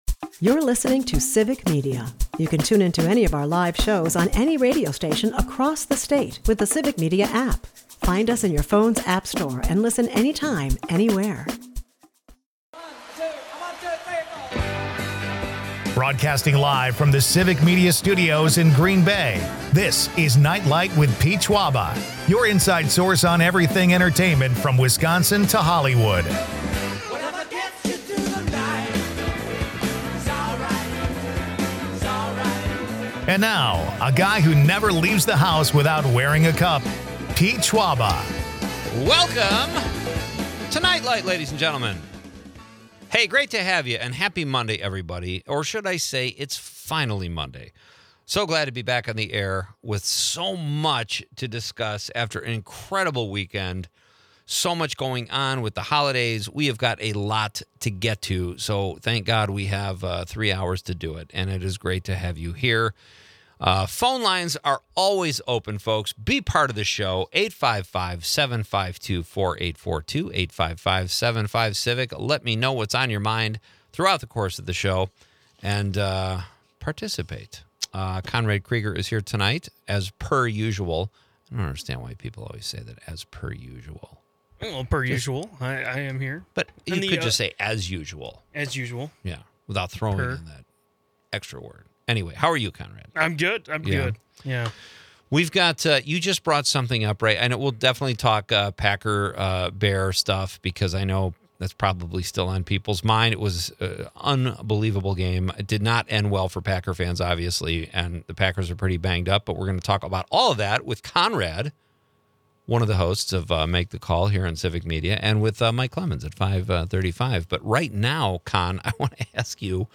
The show takes a humorous turn with Ariana Grande's surprise SNL sketch as Kevin McCallister, sparking laughs and nostalgia.